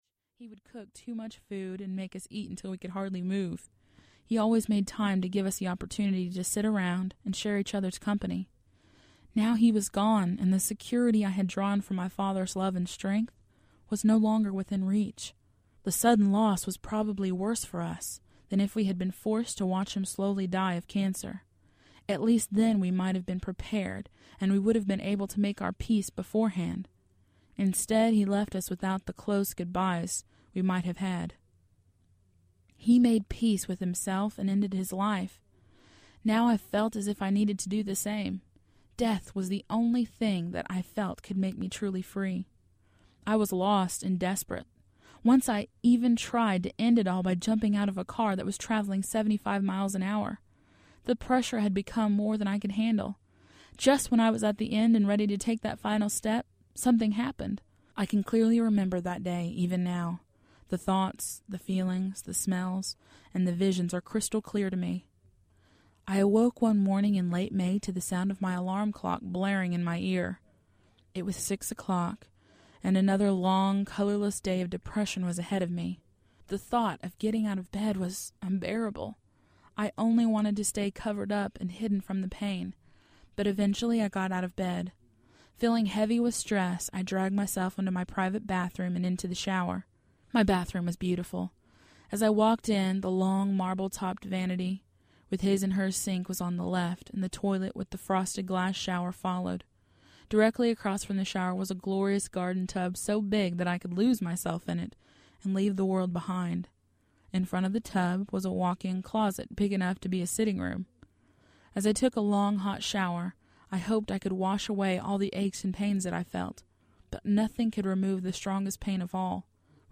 Armed with God’s Power Audiobook
Narrator
3.4 Hrs. – Unabridged